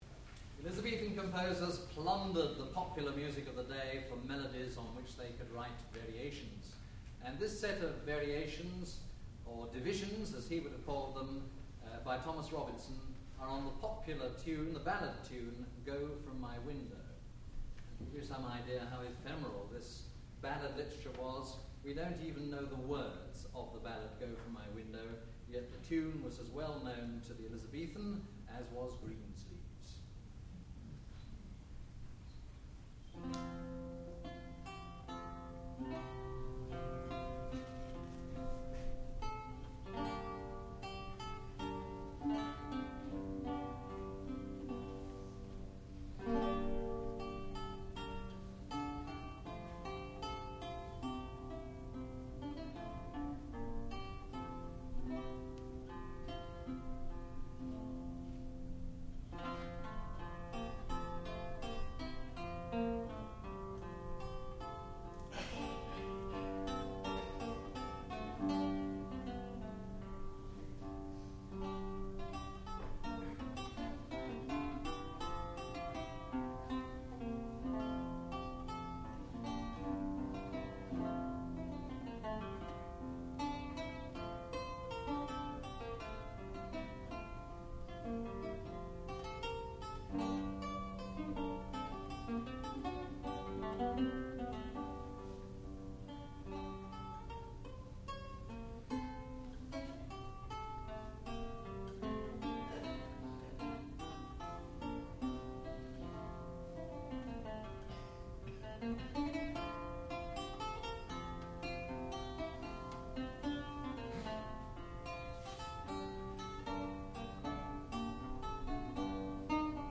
Go from my window (lute solo)
sound recording-musical
classical music
lute